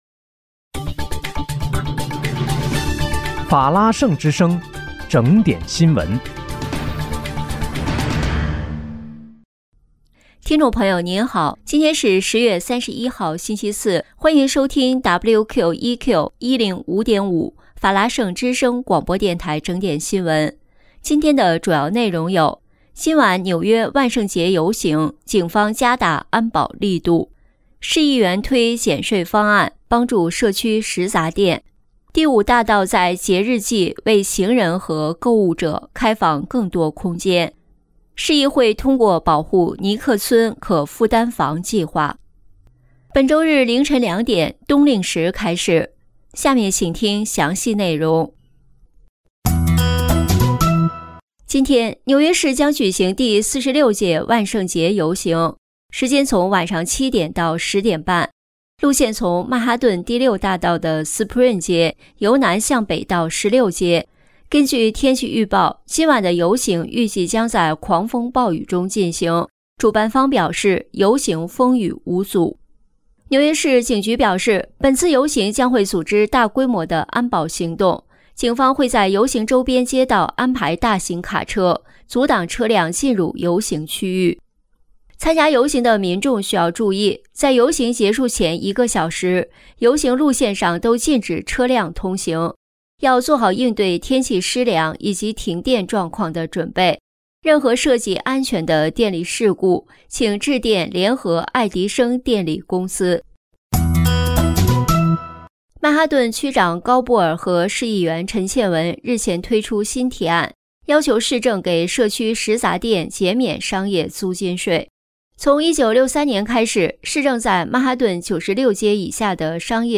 10月31日(星期四)纽约整点新闻